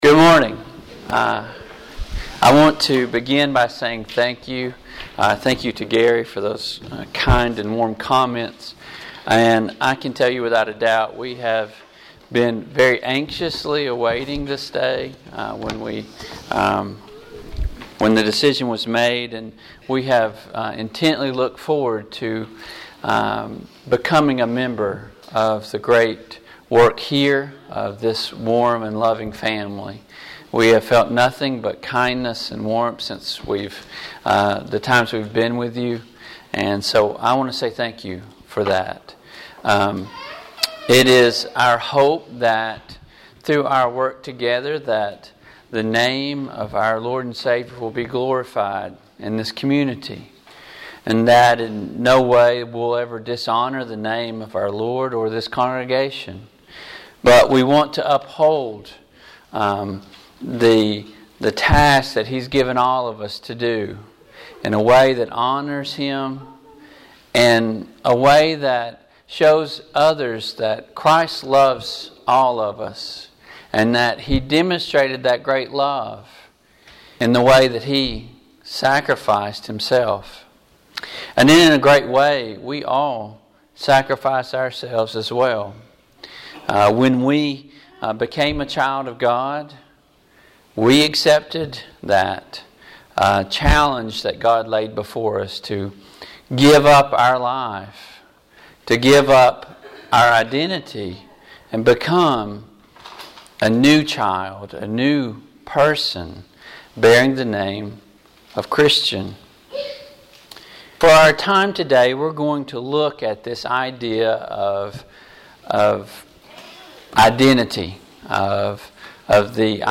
Bible Text: Daniel 1:6-7 | Preacher